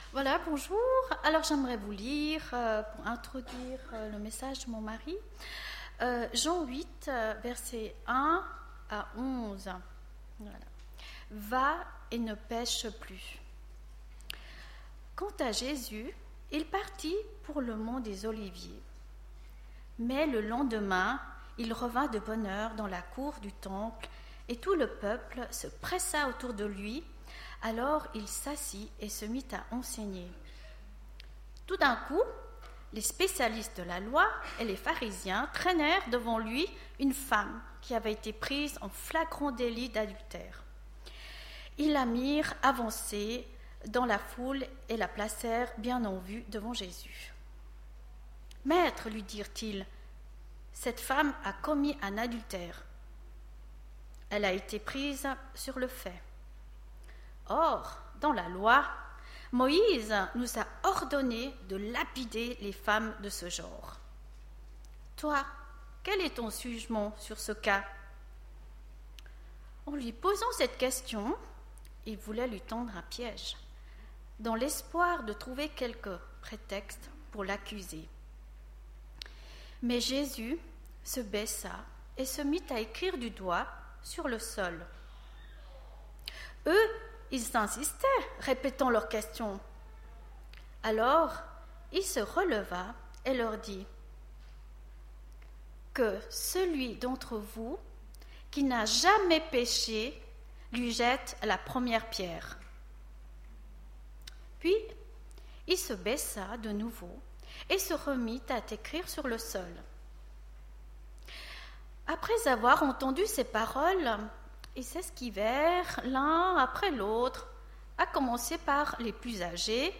Culte du 12 juillet 2015